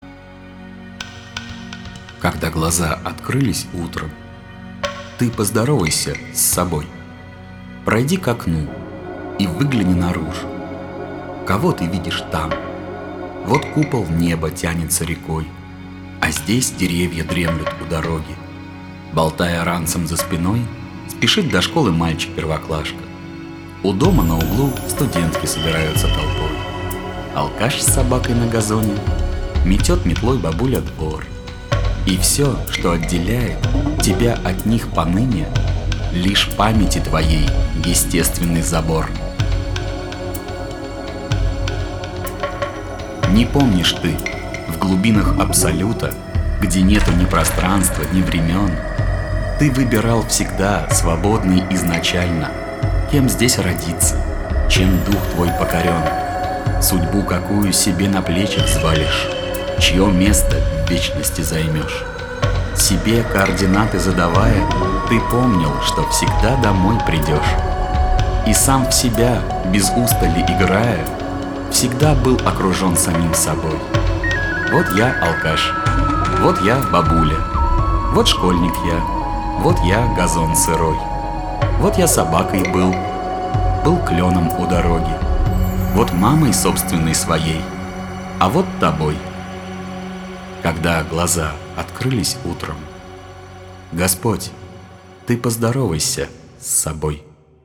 а стихи как раз в муз.сопровождении)
И голос у него такой приятный.
можно, еще как...голос проникновенный